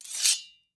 Sword Unsheath 2.ogg